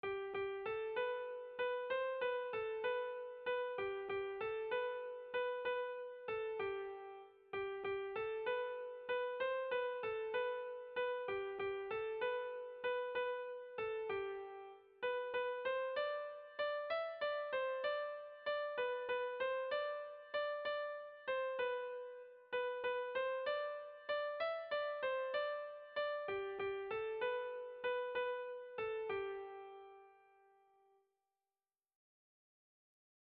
Dantzakoa
Kopla doinua.
AA